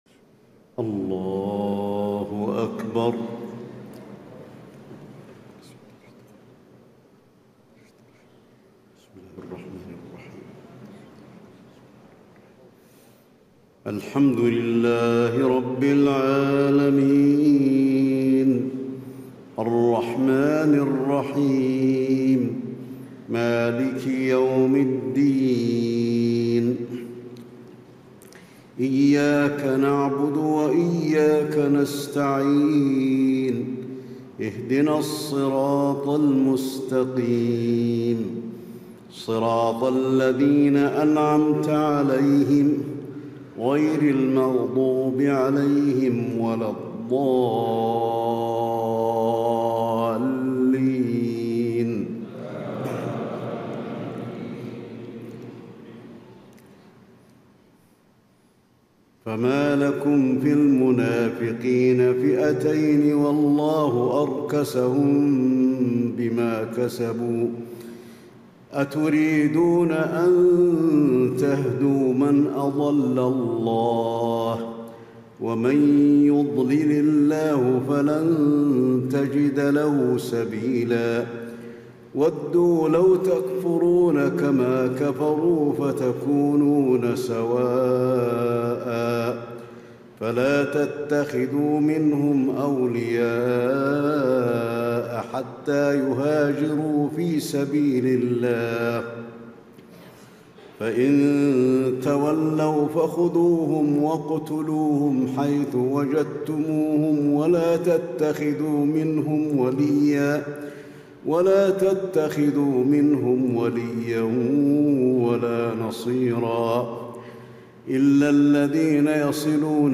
تهجد ليلة 25 رمضان 1436هـ من سورة النساء (88-147) Tahajjud 25 st night Ramadan 1436H from Surah An-Nisaa > تراويح الحرم النبوي عام 1436 🕌 > التراويح - تلاوات الحرمين